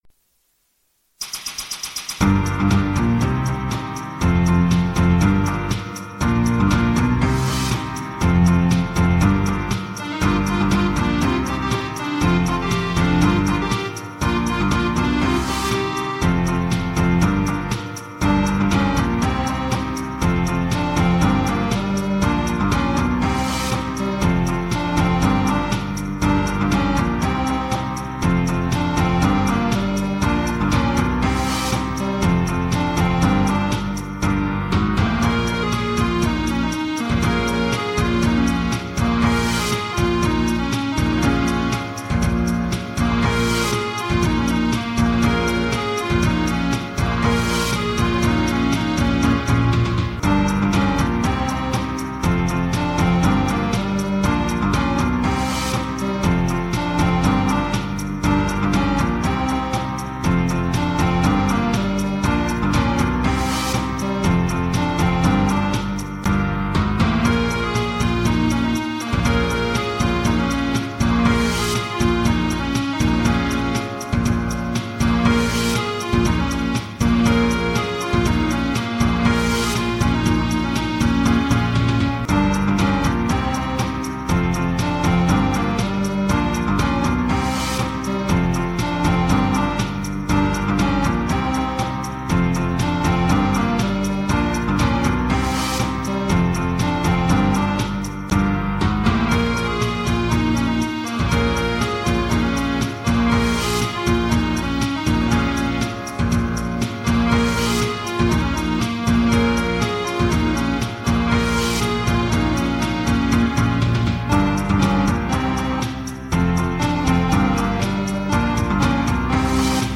Disco - Beat